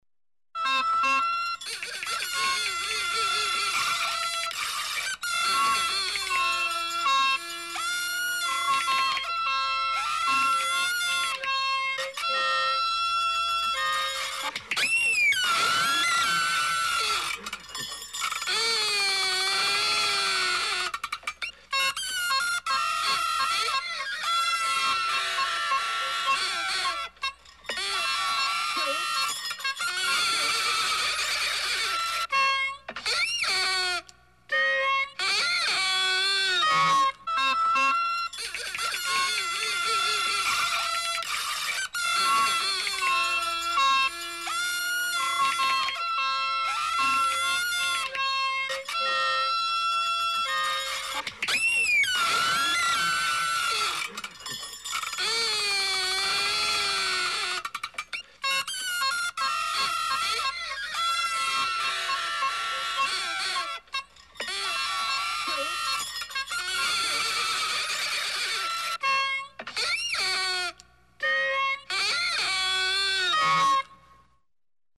Звуки детских игрушек
8. Все игрушки звучат вместе